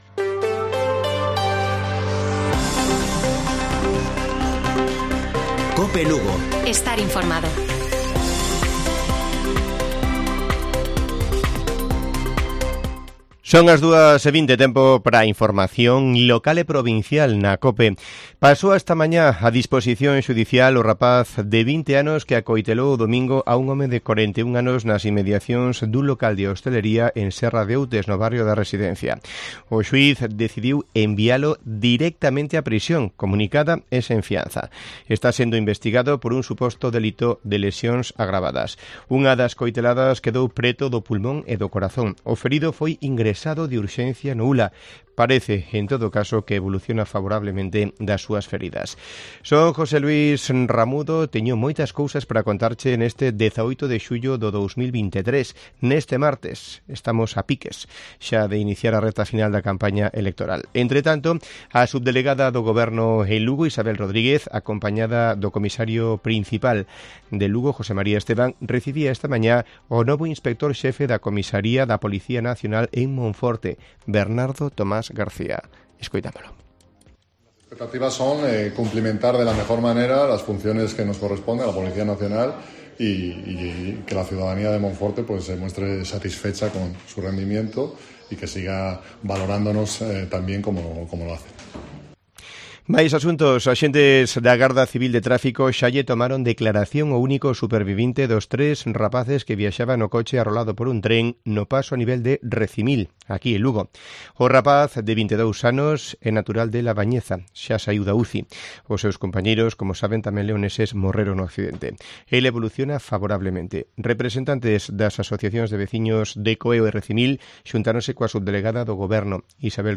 Informativo Mediodía de Cope Lugo. 18 de julio. 14:20 horas